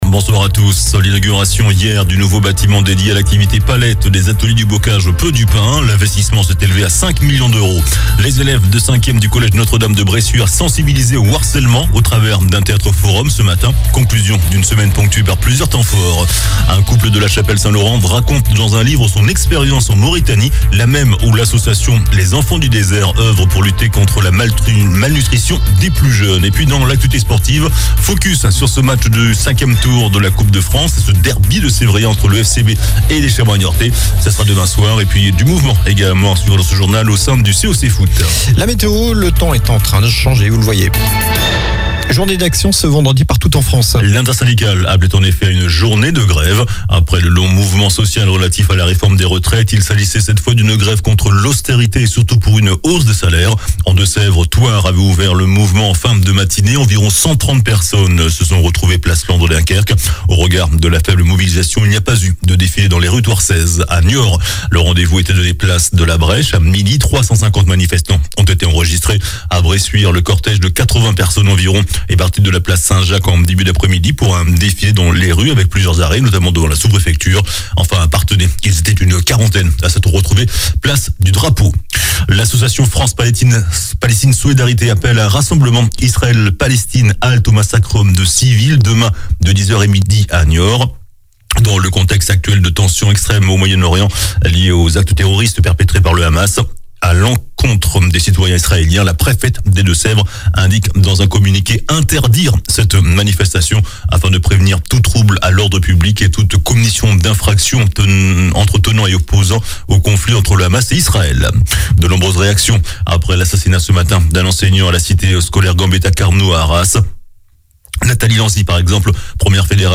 JOURNAL DU VENDREDI 13 OCTOBRE ( SOIR )